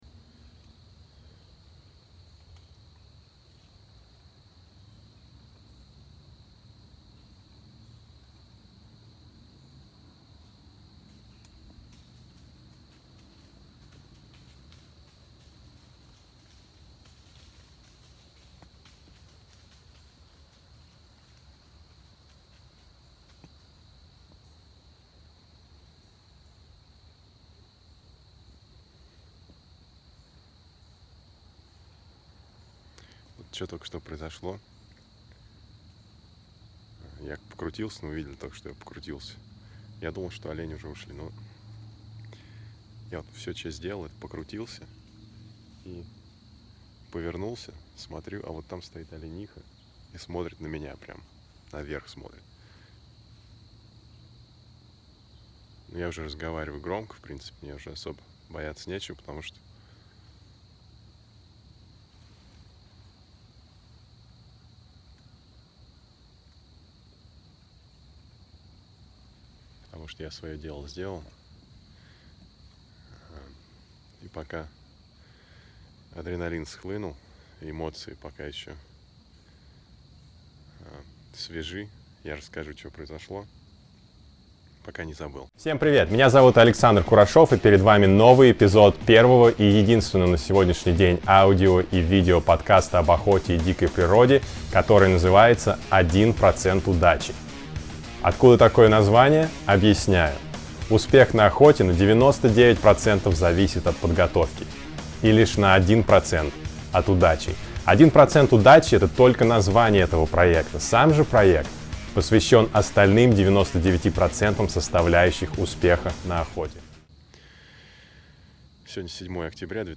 Перед вами аудио отчёт о моей успешной охоте на самца белохвостого оленя с блочным луком.